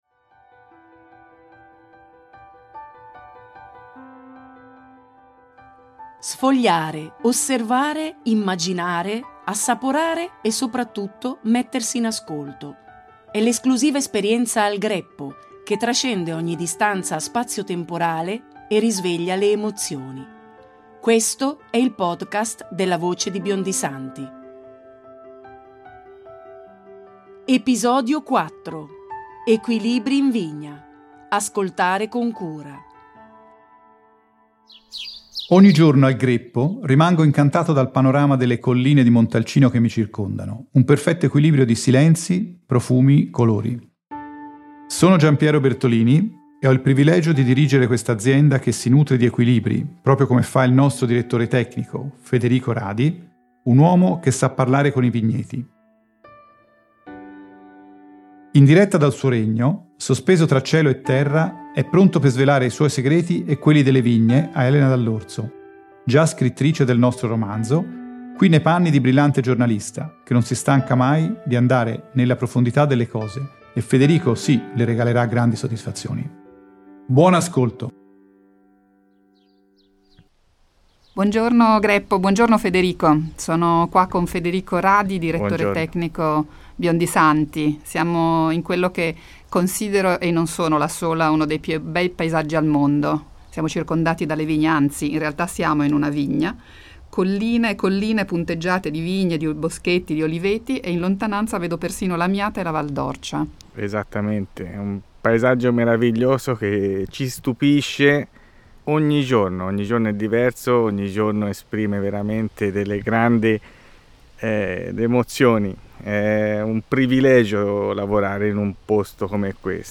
un'intervista condotta tra i vigneti